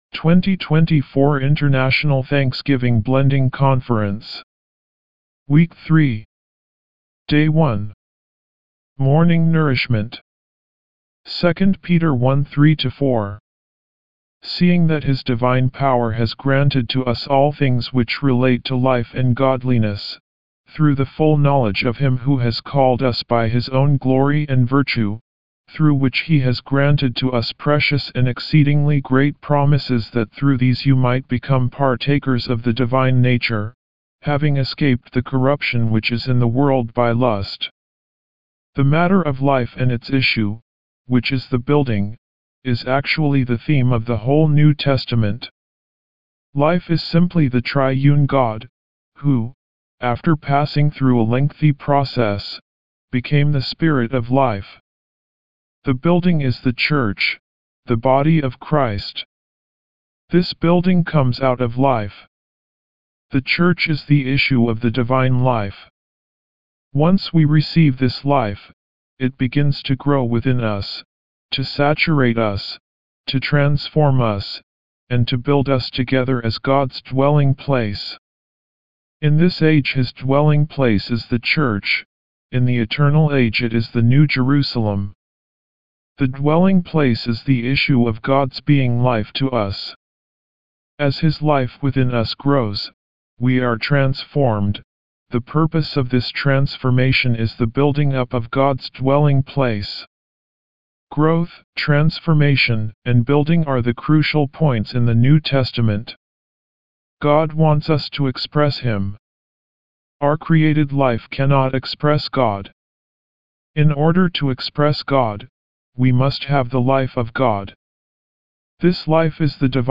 D1 English Rcite：